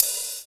34 OP HAT 2.wav